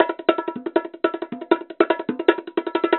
tamtam.mp3